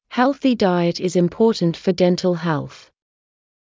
ﾍﾙｼｰ ﾀﾞｲｴｯﾄ ｲｽﾞ ｲﾝﾎﾟｰﾀﾝﾄ ﾌｫｰ ﾃﾞﾝﾀﾙ ﾍﾙｽ